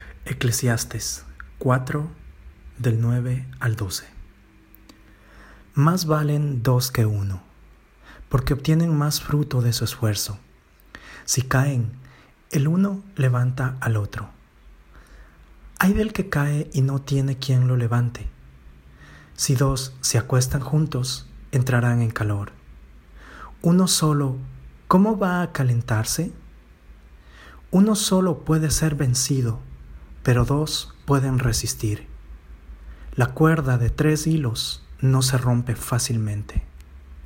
Spanisch, ruhige und angenehme Stimme
Sprechprobe: Industrie (Muttersprache):